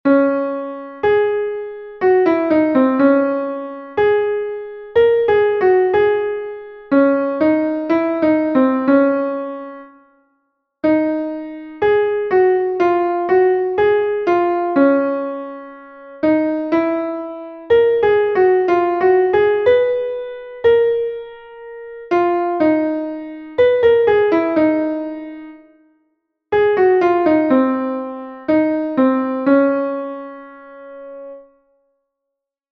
Entoación a capella
entonacioncapela10.2.mp3